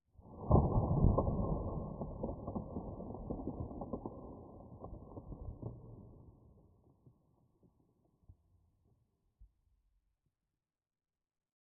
sounds / ambient / nether / basalt_deltas / plode2.ogg